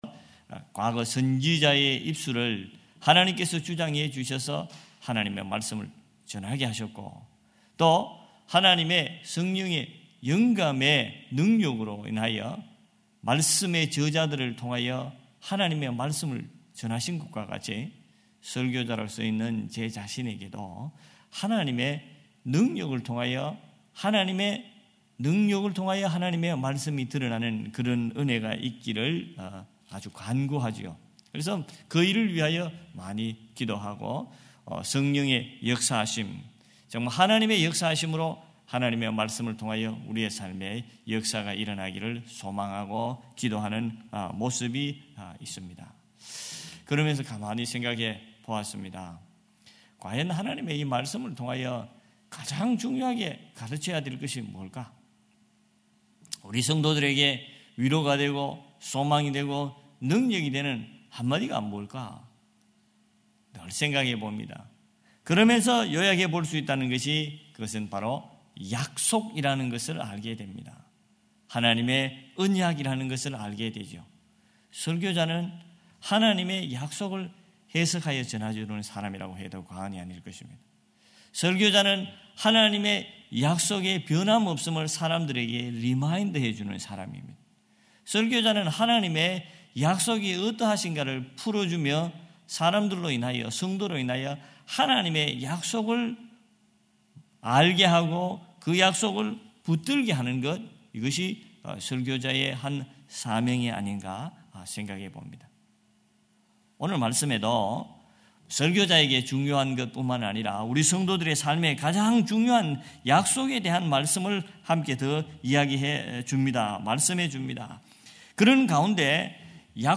July 12 2025 새벽예배